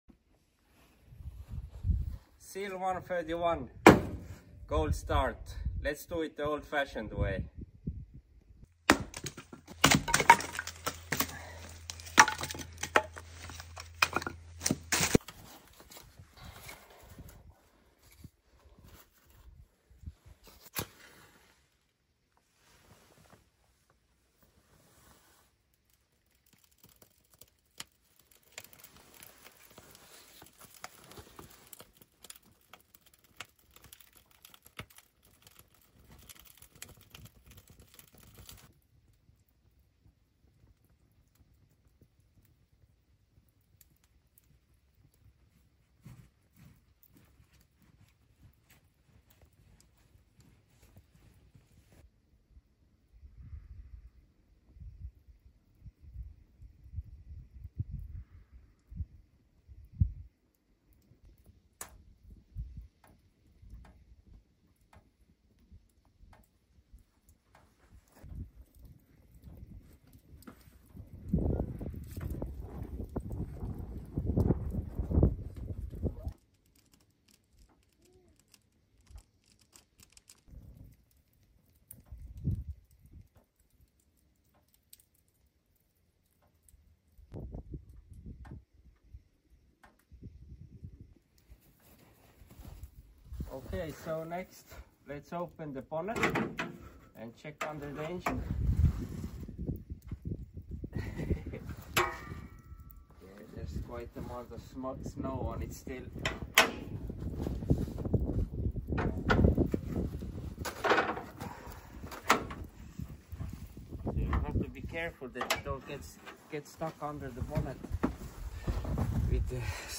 Cold start - Warming Up My ZIL 131 the Siberian Way